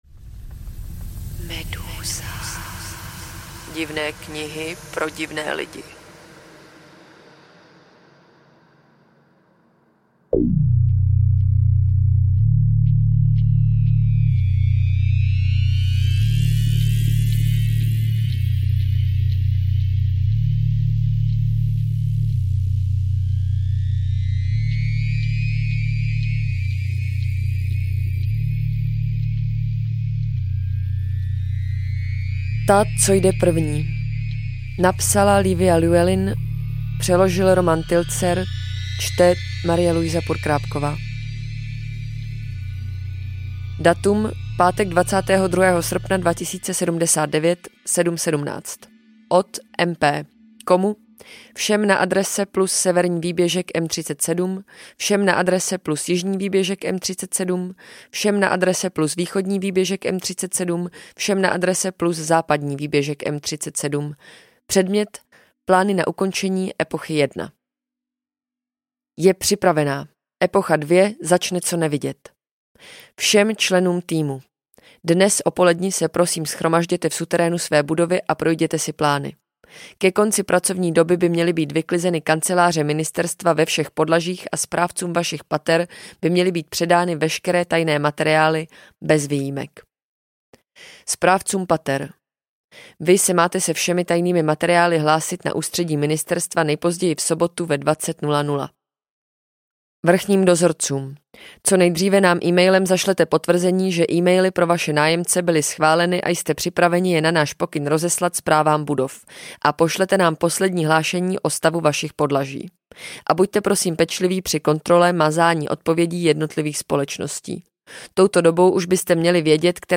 Ta, co jde první audiokniha
Ukázka z knihy